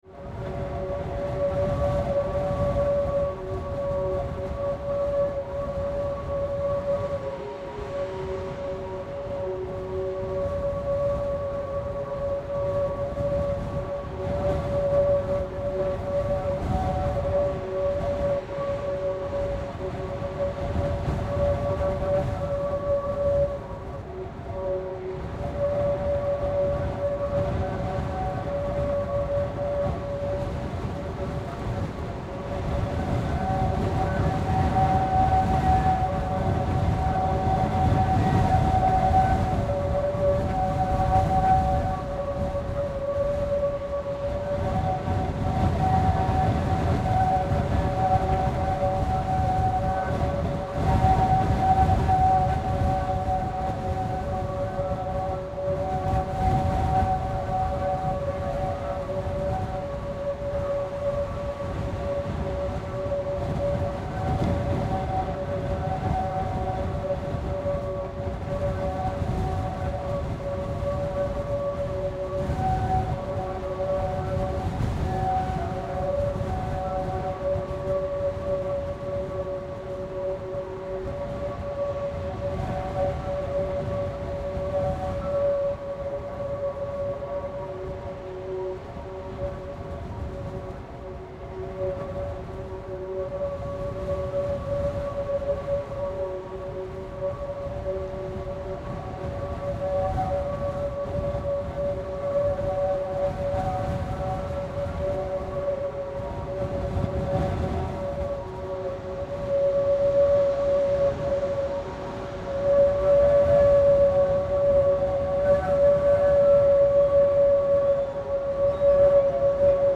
With little interruption from any kind of noise pollution the village and surrounding area features a crystal-clear soundscape of natural found-sounds.
While I was recording the wind from within the barn I was called by the films director to quickly rush over to where they were filming a quick cutaway shot 20 meters or so from the barn, here was a gate leading down to a white beach and this harmonic whistling sound. The Atlantic wind blew through holes in the rustic pipes of the gate, creating these dissonant notes.